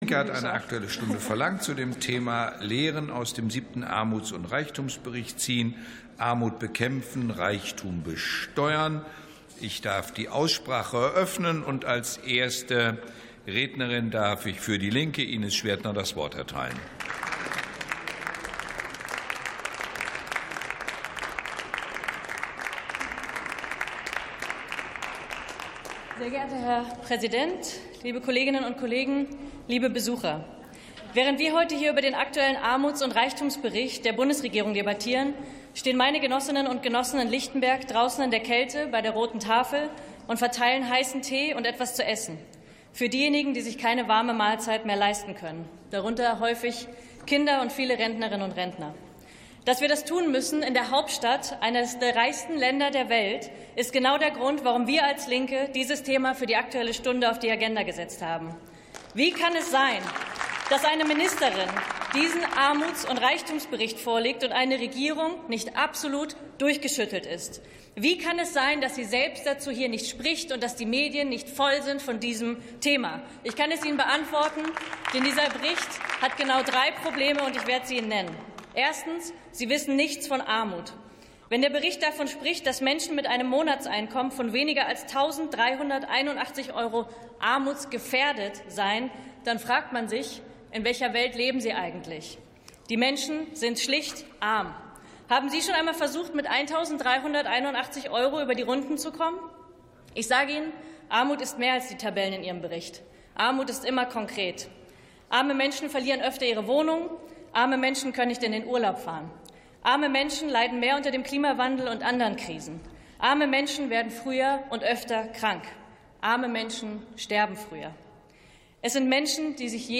49. Sitzung vom 17.12.2025. TOP ZP 1: Aktuelle Stunde zum 7. Armuts- und Reichtumsbericht ~ Plenarsitzungen - Audio Podcasts Podcast